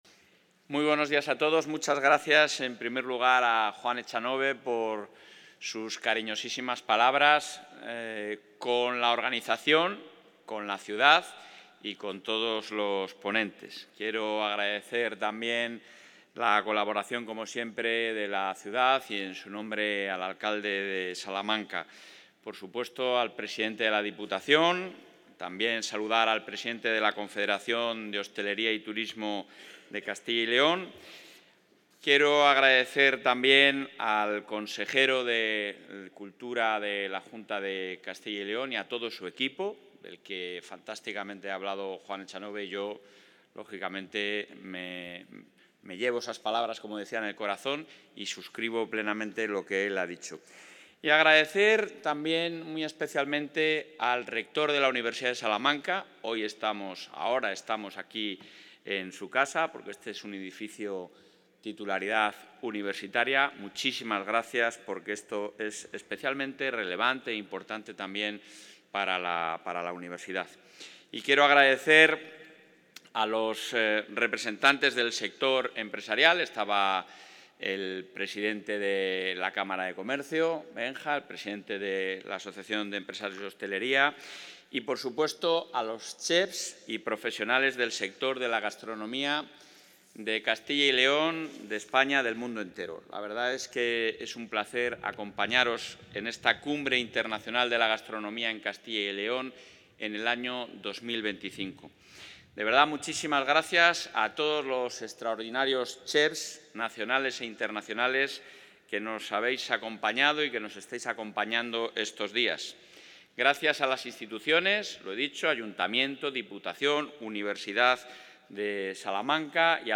Intervención del presidente.
El presidente de la Junta de Castilla y León, Alfonso Fernández Mañueco, ha participado en la I Cumbre Internacional de Gastronomía de Castilla y León 2025 'Una cocina monumental', organizada por el Ejecutivo autonómico, donde ha ensalzado el potencial de la gastronomía de la Comunidad, todo un referente de calidad y elemento fundamental en su proyección internacional.